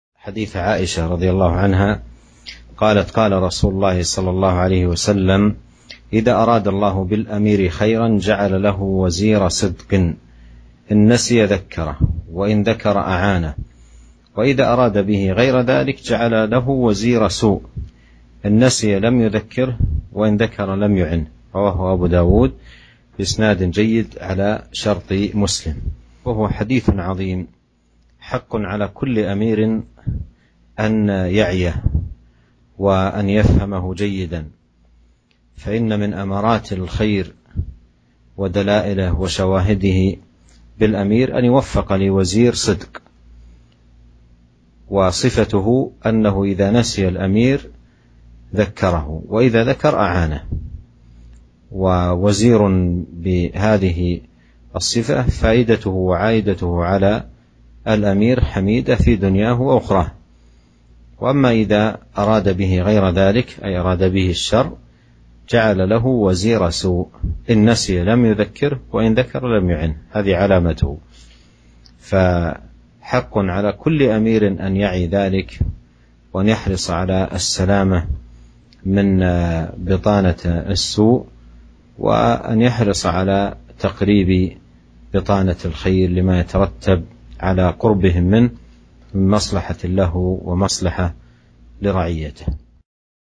شرح حديث إذا أراد الله بالأمير خيراً جعل له وزير صدق